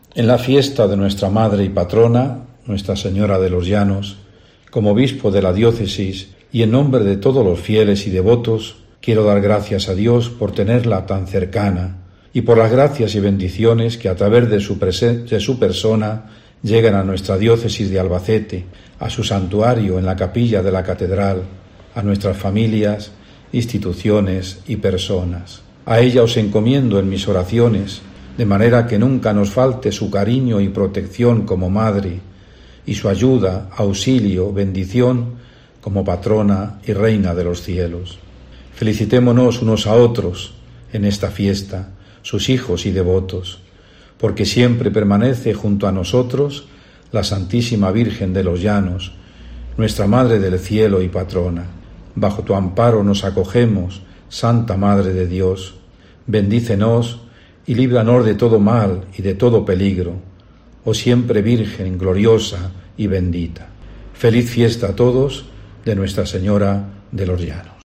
Felicitación del Obispo de Albacete en el día de la Patrona, la Virgen de los Llanos
Don Ángel Fernánez Collado se dirige a los albaceteños en este día tan especial y raro a la vez en el que no debe faltar la devoción a la Patrona